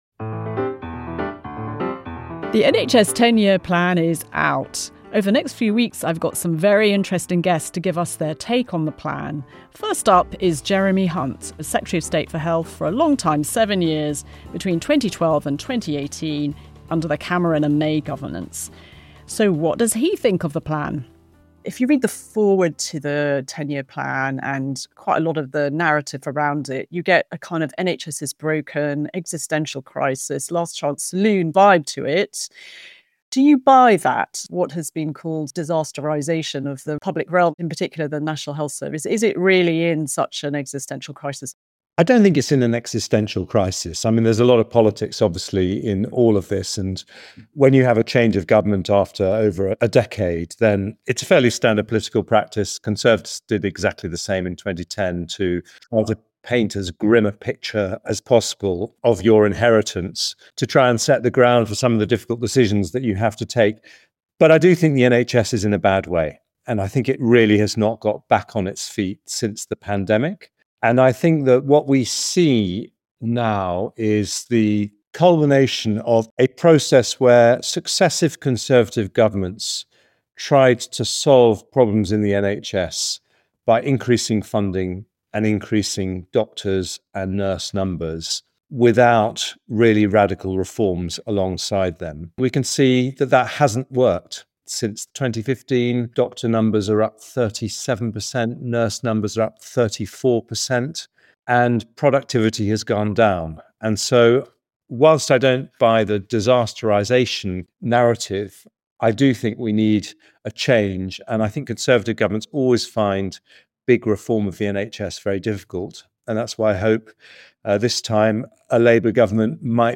In the first of two special episodes, we speak to Jeremy Hunt about the state of the NHS and his reaction to the government’s 10-Year Health Plan.